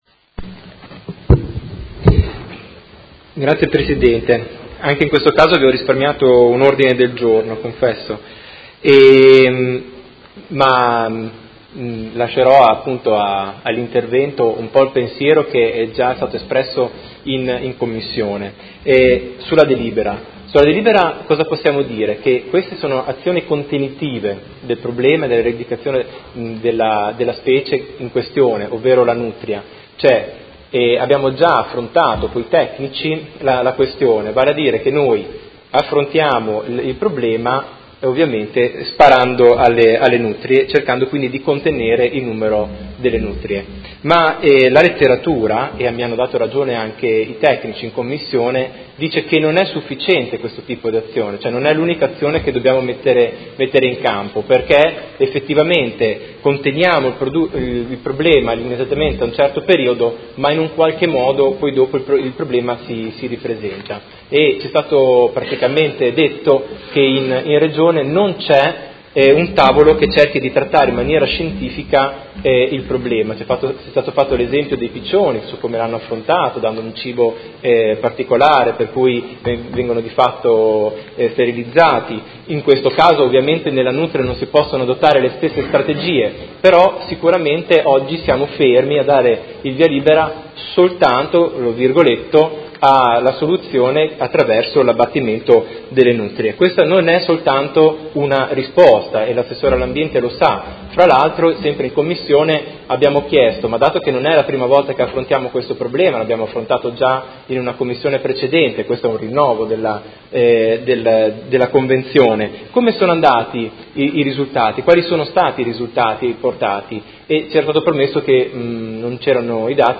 Marco Chincarini — Sito Audio Consiglio Comunale
Seduta del 12/04/2018 Dibattito. Approvazione Convenzione per il controllo della specie nutria (Myocastor coypus)